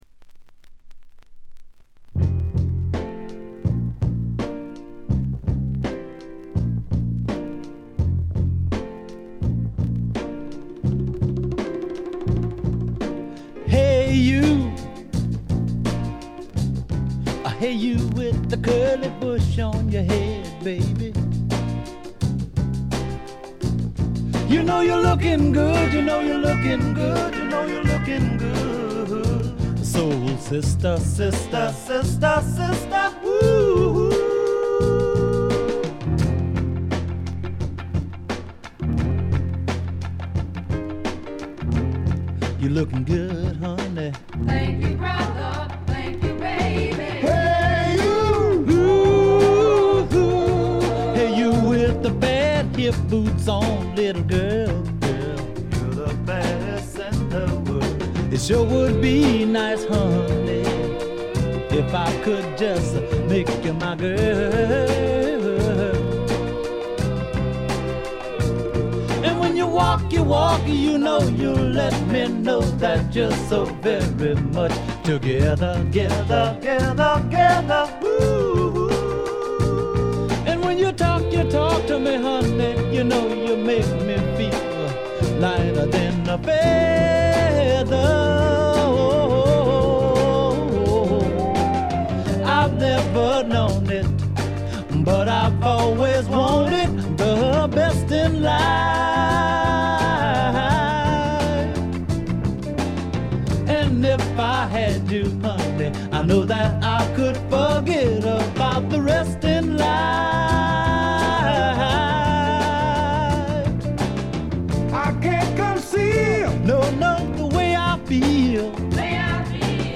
鑑賞を妨げるようなノイズはありません。
セカンドライン・ビート、ニューソウル的なメロウネス、何よりも腰に来るアルバムです。
試聴曲は現品からの取り込み音源です。